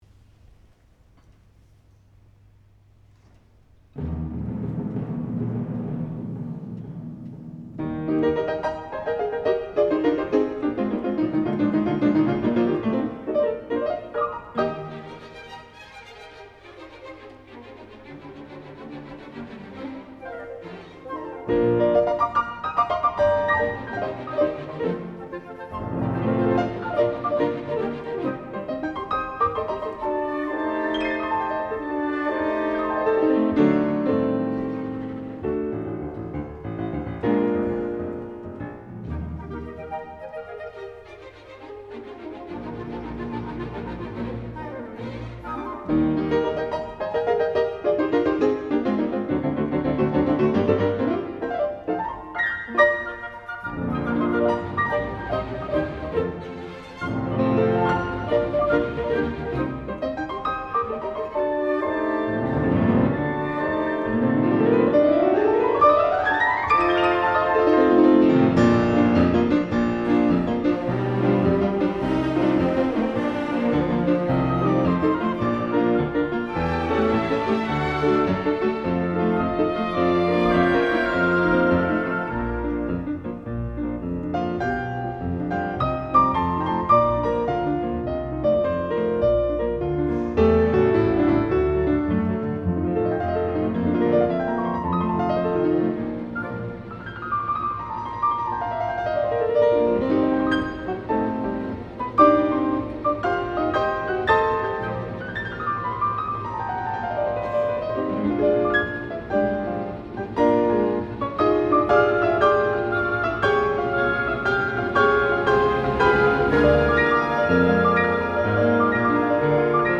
The orchestra is scored for 2 flutes, 2 oboes, 2 clarinets, 2 bassoons, 2 horns, 2 trumpets, timpani, crash cymbals and strings.
Instead of a typical Adagio, we have a leggieramente scherzo in E Flat Major.
The piano part is characterised by many flourishing semiquaver passages all over the keyboard, as is of considerable technical difficulty.
I recently played this concerto in Cape Town with the Cape Philharmonic Orchestra in May 2010.